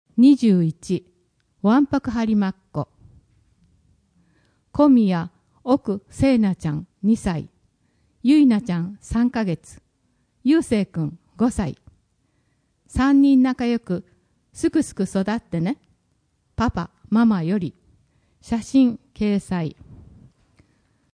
声の「広報はりま」8月号
声の「広報はりま」はボランティアグループ「のぎく」のご協力により作成されています。